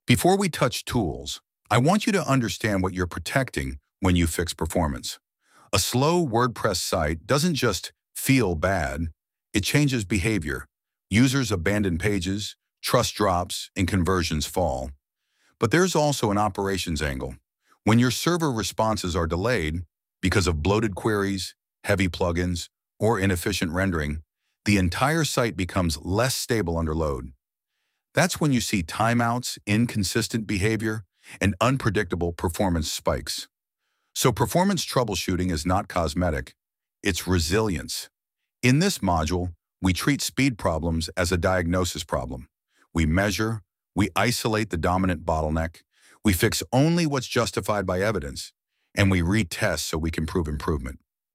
Lesson-2.1-VO-Step-2.mp3